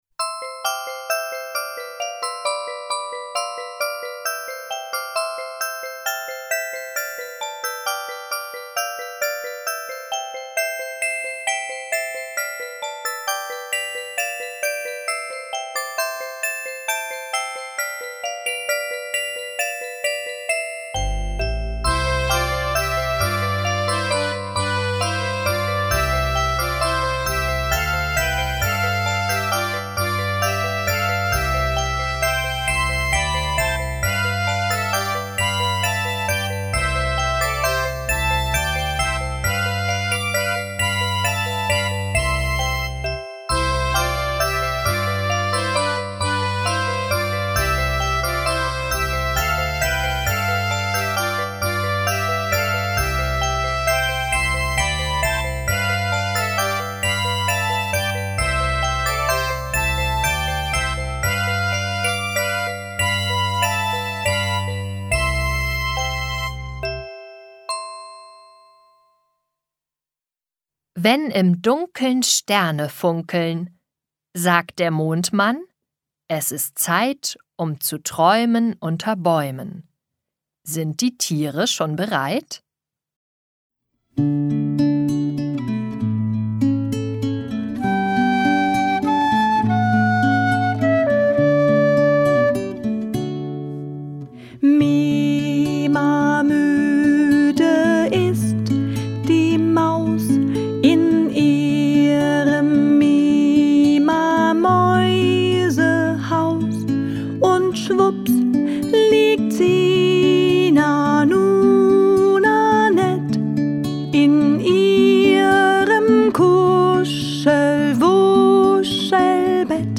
Eine Geschichte mit Liedern zur guten Nacht